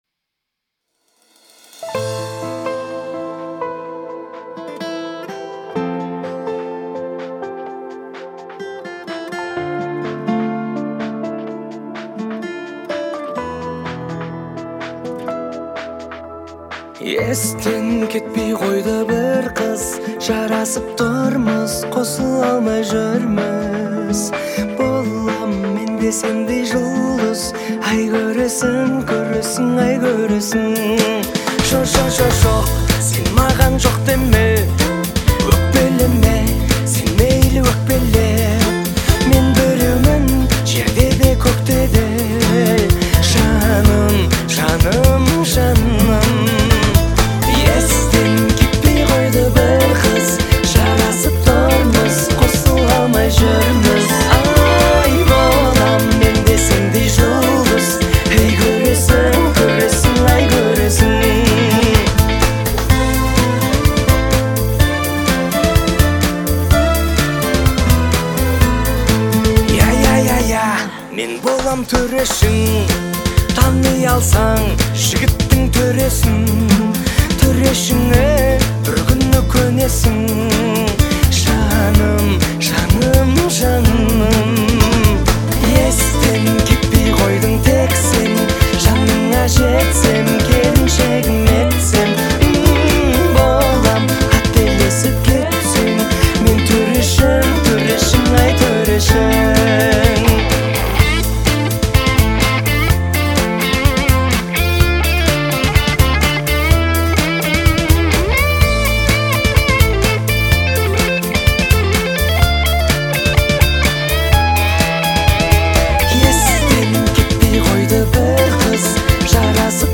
Интересная мелодия и харизматичное исполнение